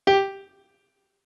MIDI-Synthesizer/Project/Piano/47.ogg at 51c16a17ac42a0203ee77c8c68e83996ce3f6132